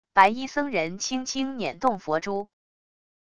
白衣僧人轻轻捻动佛珠wav下载